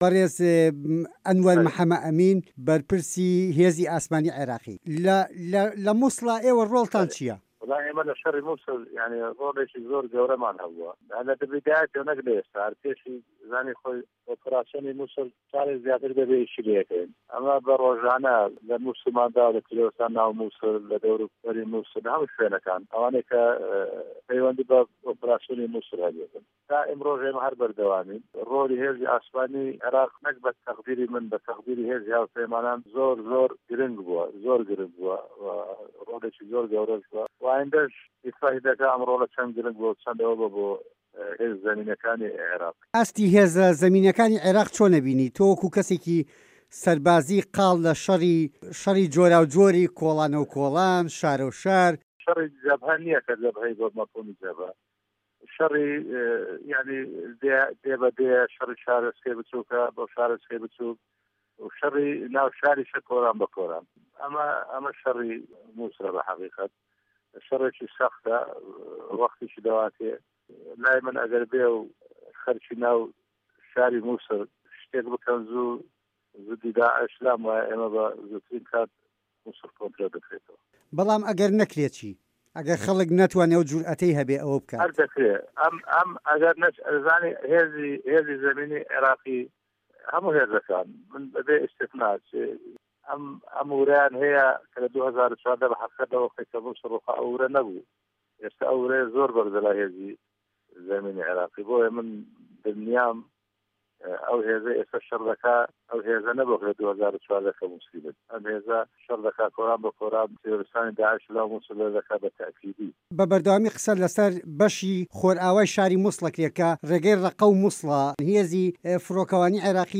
وتووێژ لەگەڵ فه‌ریق روکن ئه‌نوه‌ر حه‌مه‌ ئه‌مین